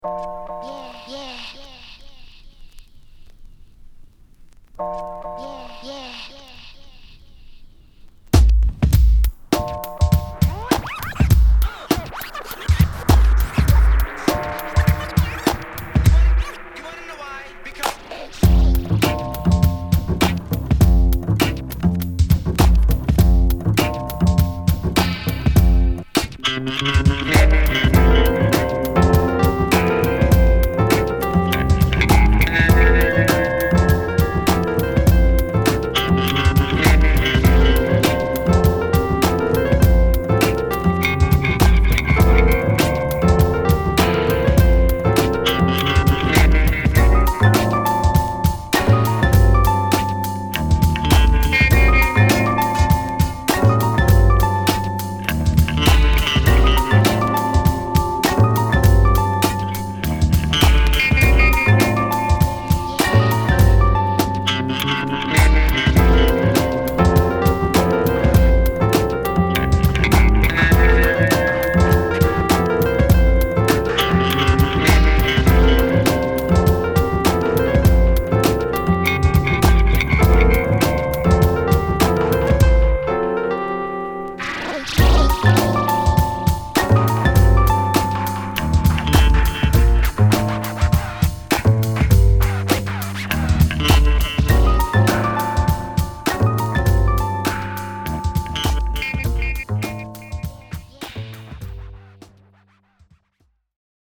・ HIP HOP UNDERGROUND 12' & LP
Dope Jazzy Breakbeats!!